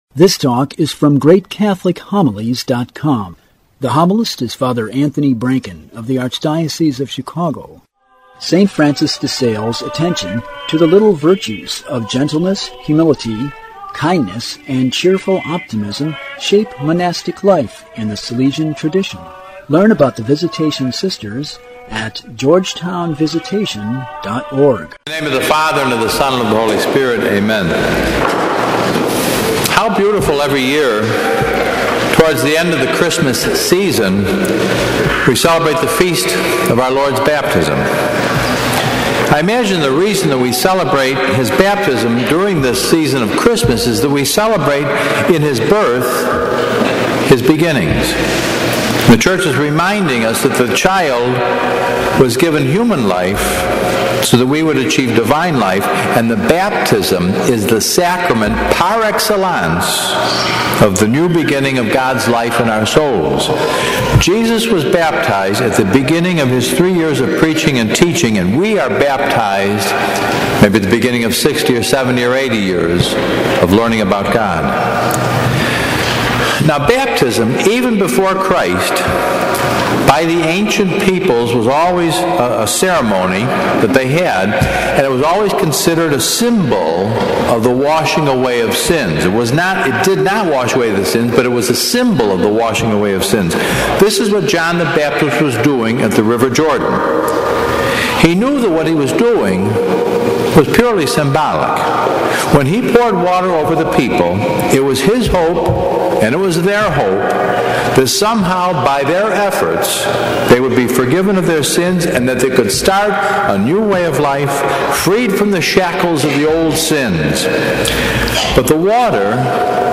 Don't wait forever to baptize your child - Great Catholic Homilies